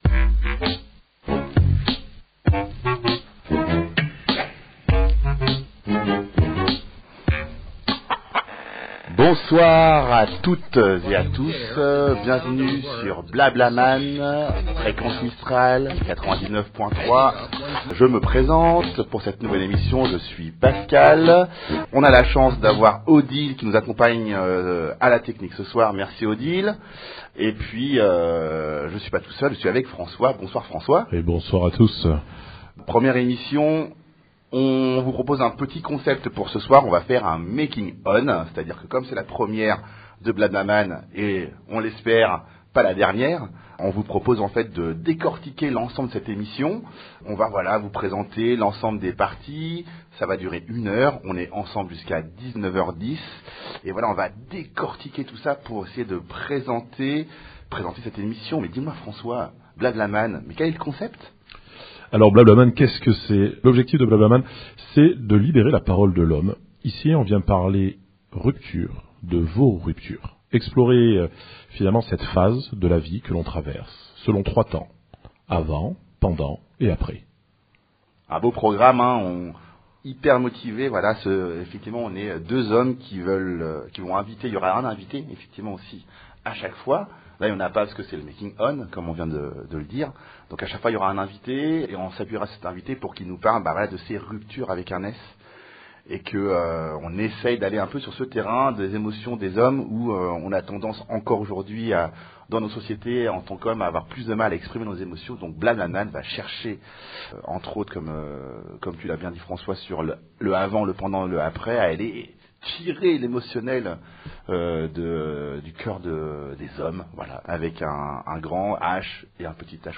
Mercredi 29 Octobre 2025 BlaBlaMan, c’est le talk qui donne la parole aux hommes pour évoquer les transformations liées aux ruptures que nous traversons dans la vie.